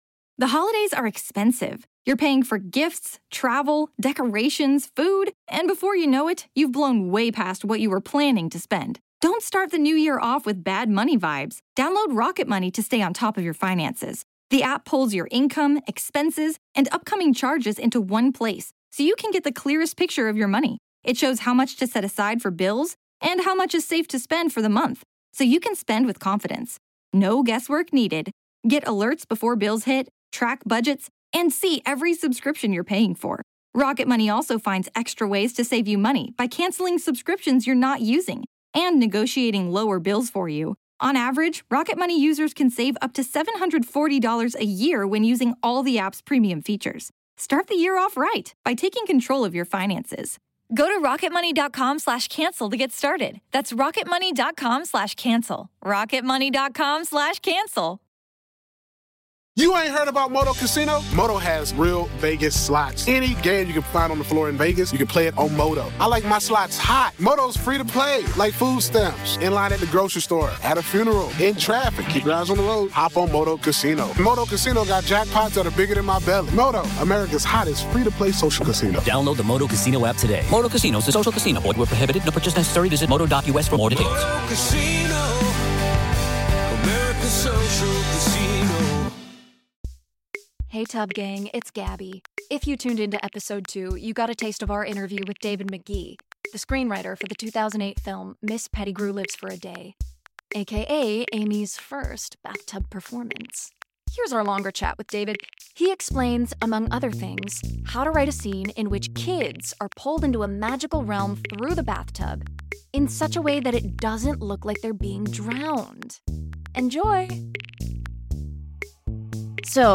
*Bonus* An Interview with David Magee